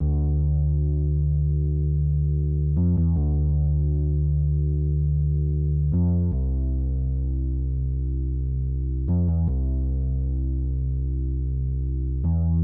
步行低音提琴
描述：周围环境的低音补丁
Tag: 76 bpm Ambient Loops Bass Loops 2.13 MB wav Key : Unknown